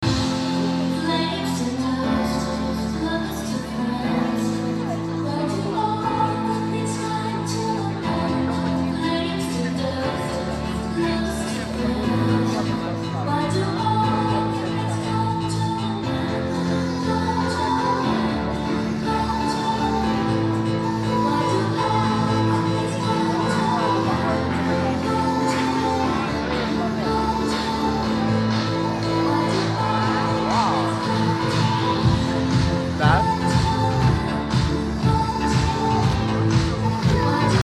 EuroDisco.mp3